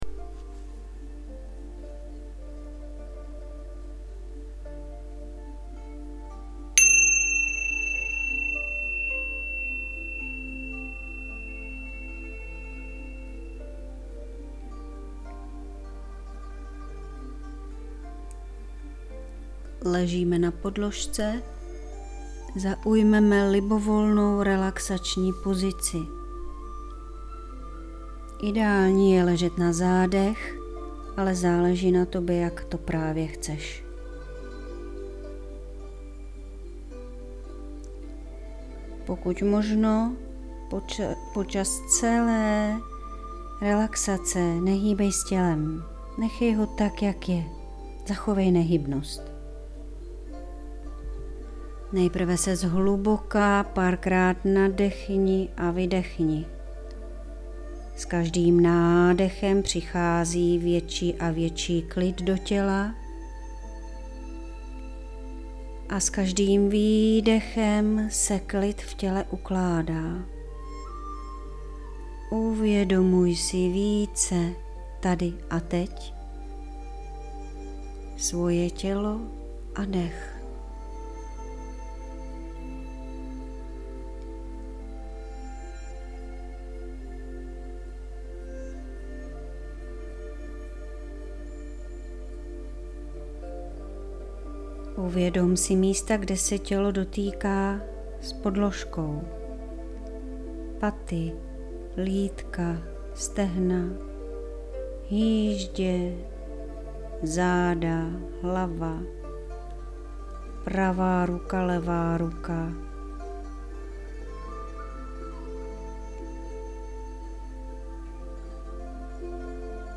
joga-nidra-sankalpa-bonsai-mp3